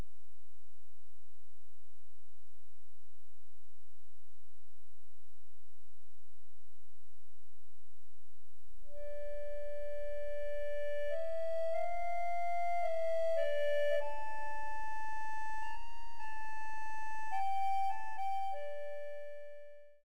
button accordion